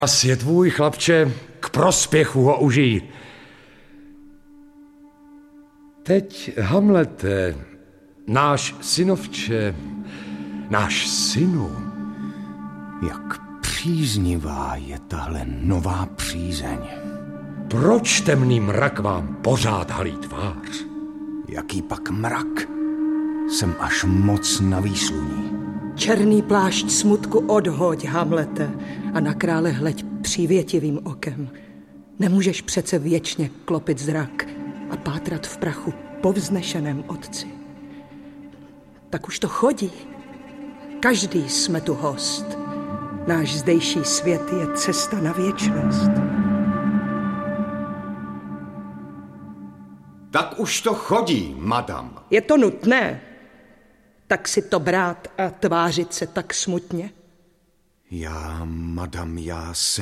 Audiobook
Read: David Novotný